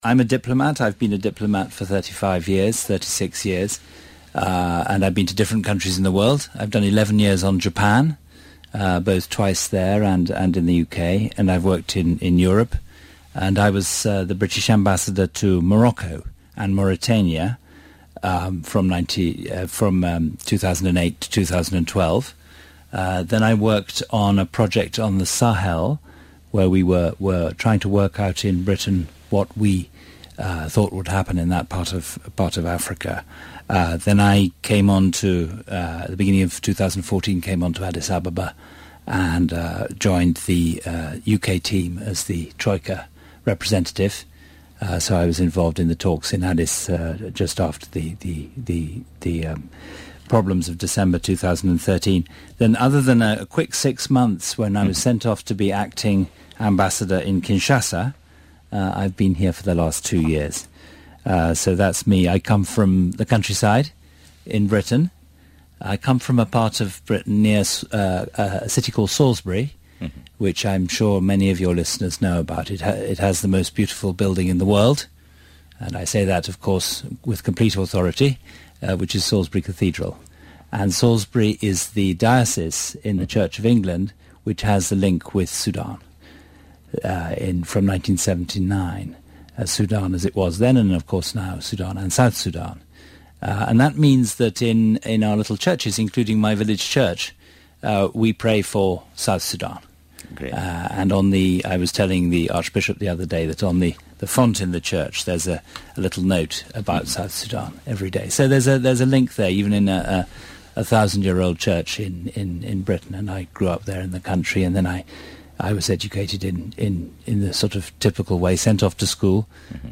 Exit Interview with Ambassador Tim Morris